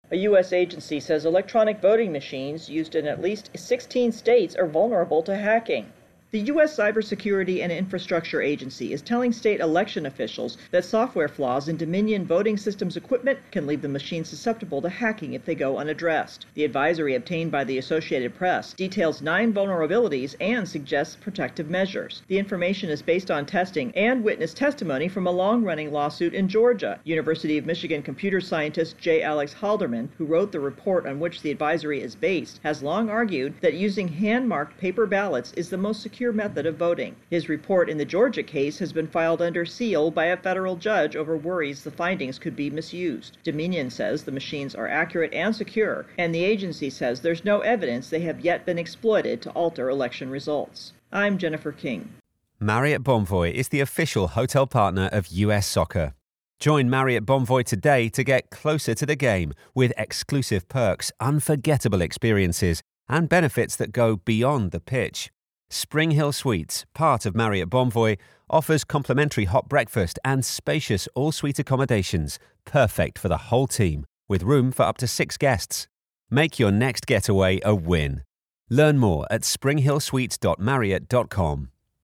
Voting Machines voicer with intro